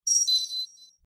Sci Fi User Interface 02
Sci-fi_user_interface_02.mp3